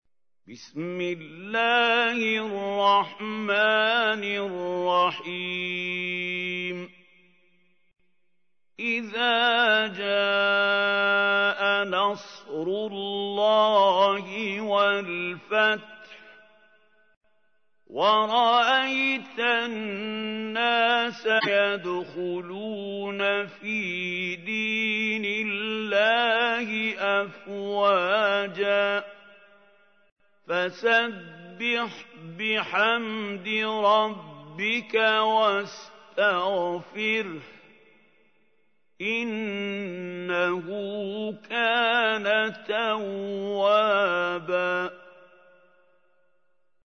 تحميل : 110. سورة النصر / القارئ محمود خليل الحصري / القرآن الكريم / موقع يا حسين